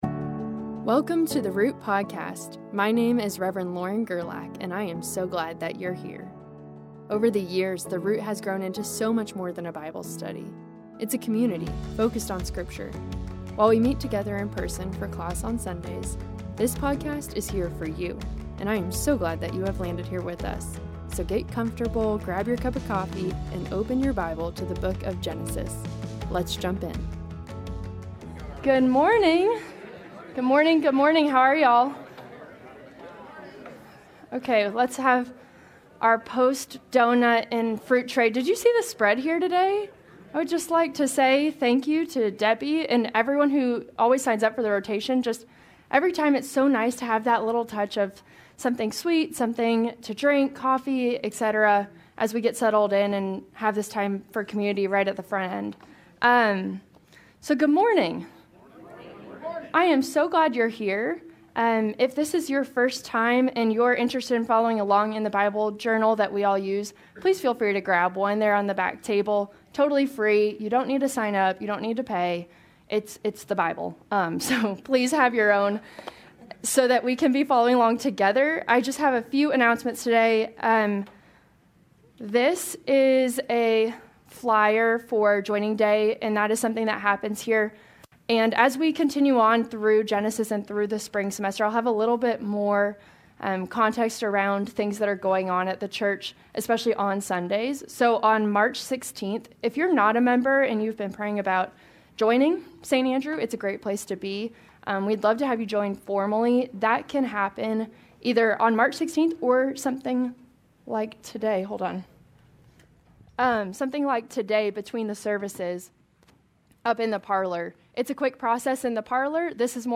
A message from the series "Genesis."